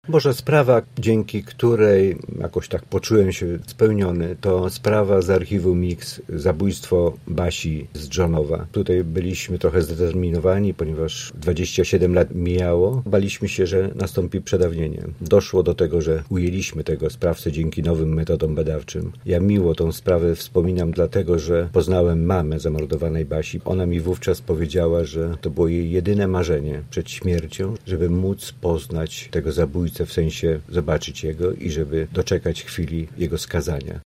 Na naszej antenie wspominał najtrudniejsze sprawy w karierze: